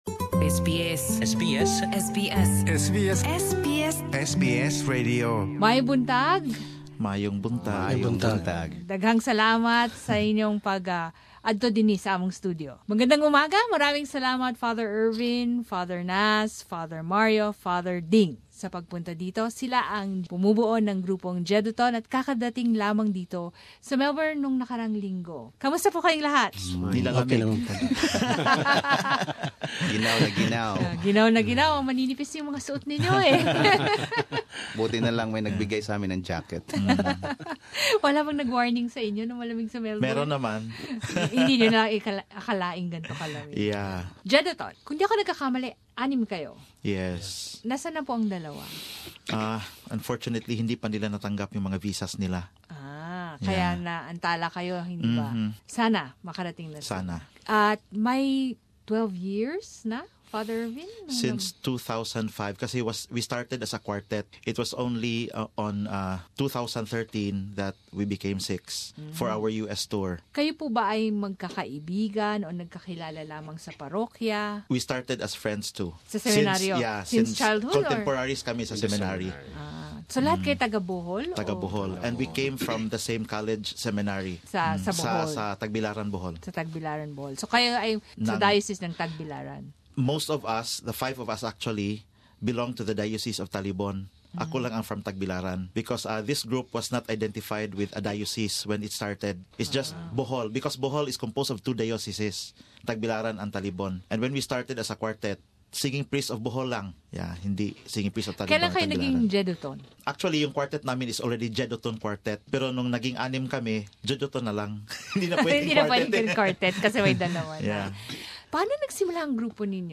Praises through song, the singing priests from Bohol visit Filipinos around the globe to spread the mesaage of love and faith. Image: Jeduthun, singing priests from Bohol at SBS studio in Melbourne (SBS Filipino)